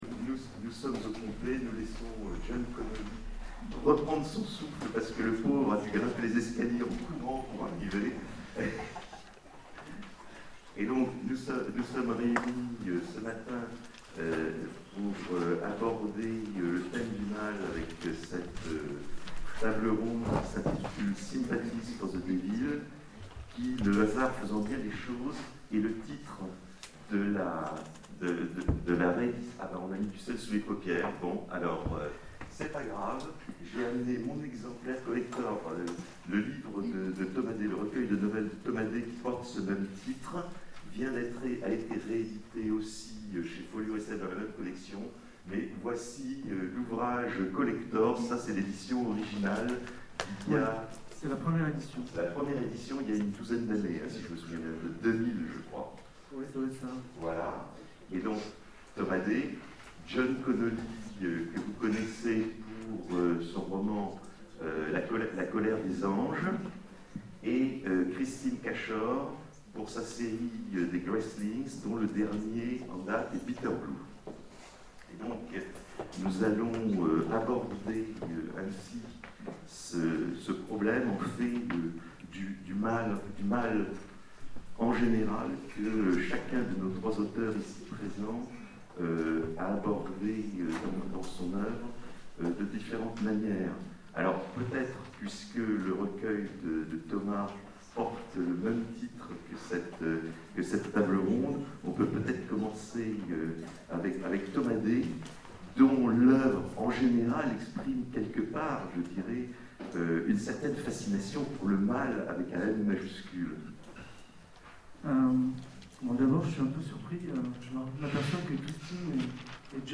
Etonnants Voyageurs 2013 : Conférence Sympathies for the devil